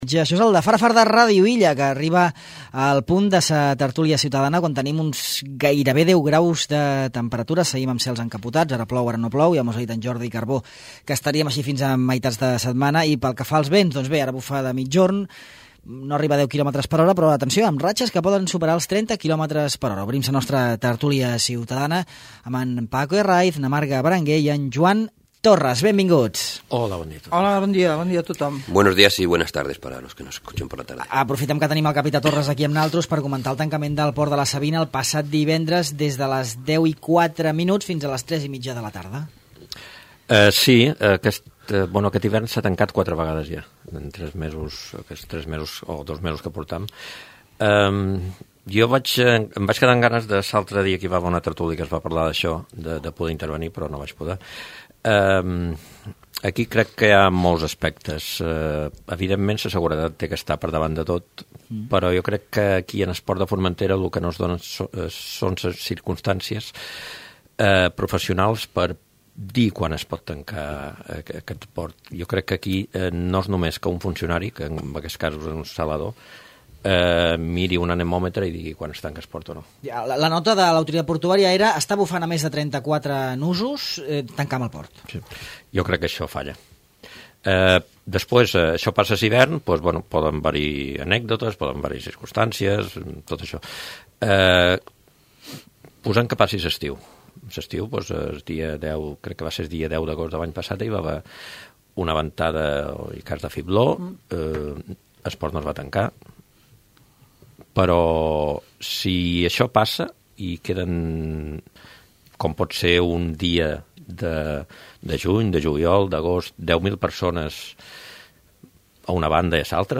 La tertúlia parla del tancament del port pels temporals